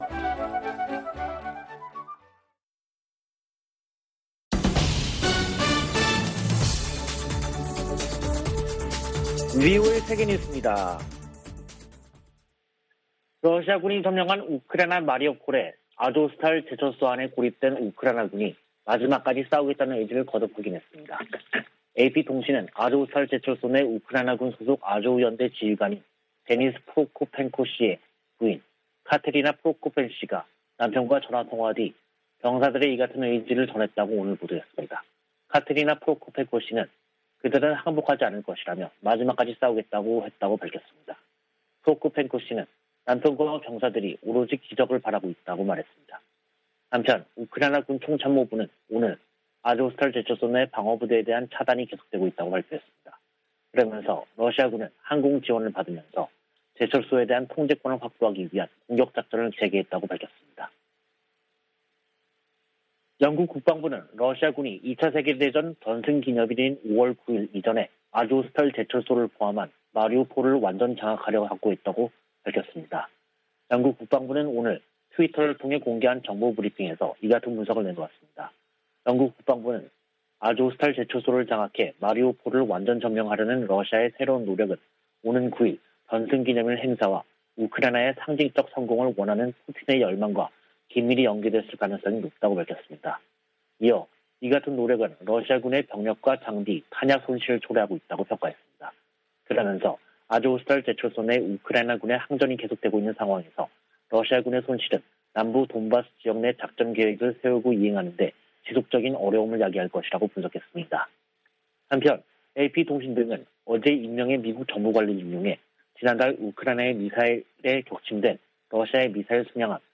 VOA 한국어 간판 뉴스 프로그램 '뉴스 투데이', 2022년 5월 6일 3부 방송입니다. 백악관은 조 바이든 대통령의 한일 순방에서 ‘확장억지’ 약속과, 북한 문제가 중점 논의 될 것이라고 밝혔습니다. 미 국무부는 북한의 거듭되는 미사일 발사에 대응이 따를 것이라는 분명한 신호를 보내야 한다고 강조했습니다. 미 상원이 필립 골드버그 주한 미국대사 인준안을 가결했습니다.